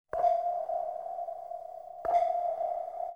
Sms сообщение
Капли